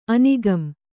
♪ anigam